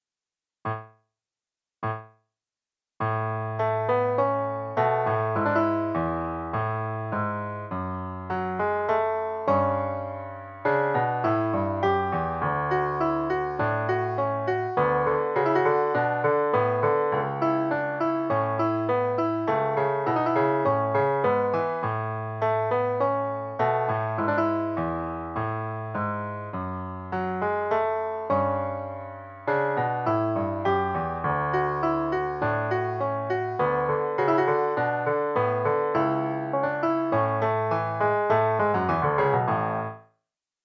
間奏のハーフスピード（テンポ51）＋1オクターヴ下ヴァージョン（音が出ます）